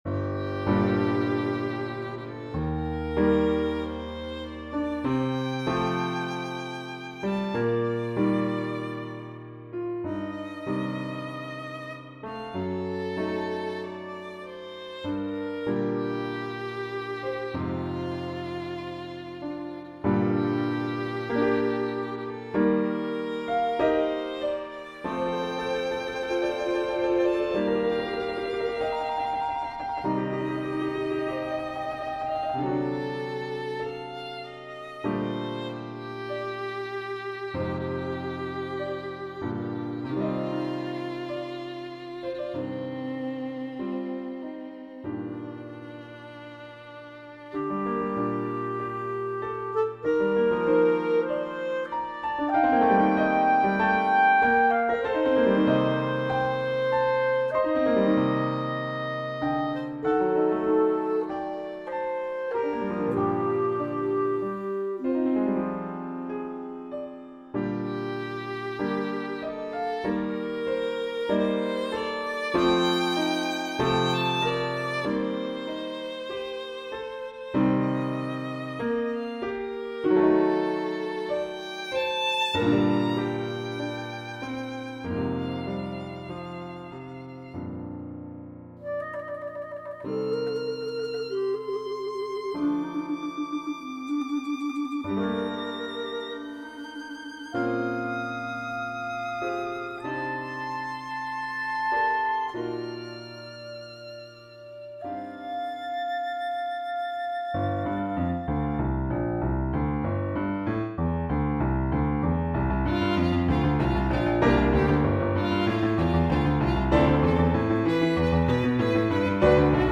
The World Turns for violin, clarinet and piano
His piece, entitled 'The World Turns', was written for violin, clarinet and piano.
This composition explores the dichotomies that define our world. The languid tranquility of the night versus the frenetic bustle of the daytime.
Through evolving harmonic language and shifting melodic ideas, the piece transitions from serene simplicity to intricate complexity, and back again.